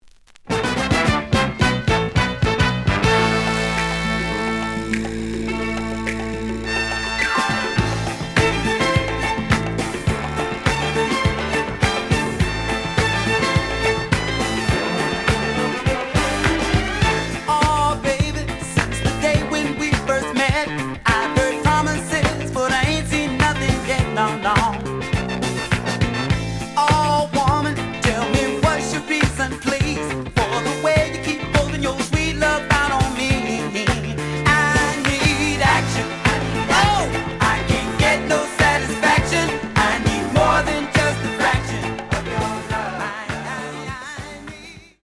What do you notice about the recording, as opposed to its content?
The audio sample is recorded from the actual item. A side plays good.